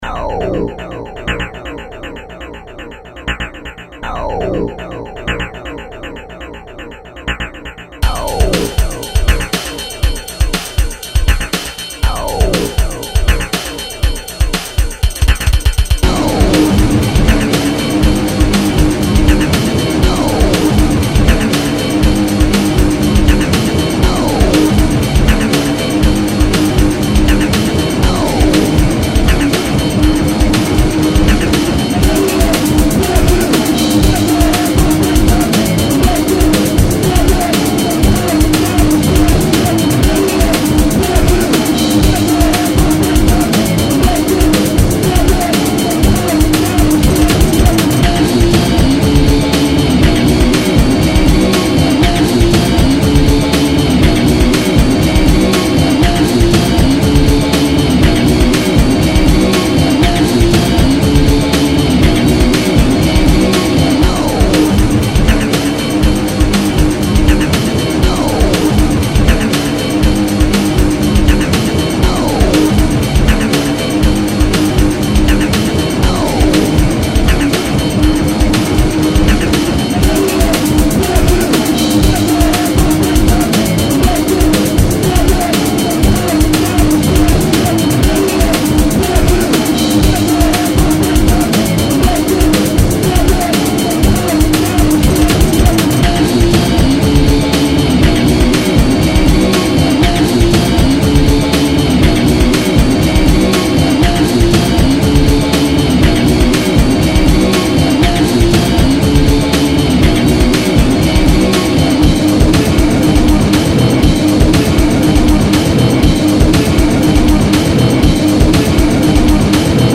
hard core punk drug band